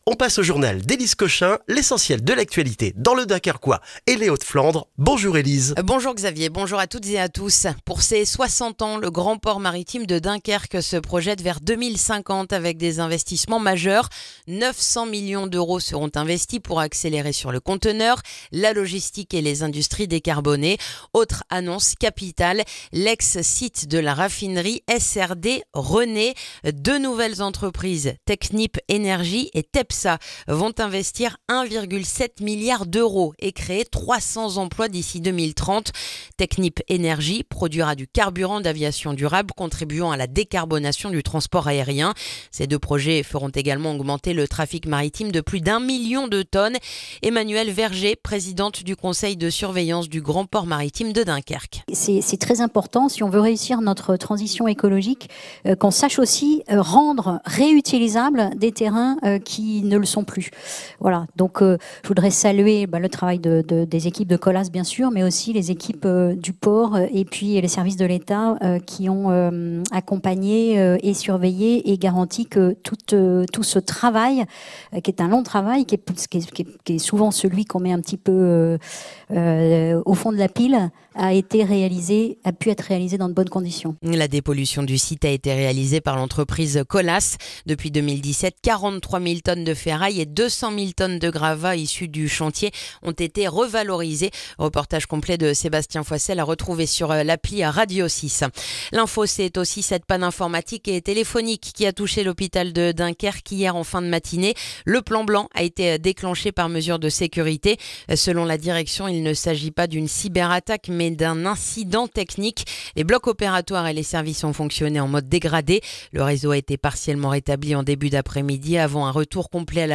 Le journal du mercredi 21 janvier dans le dunkerquois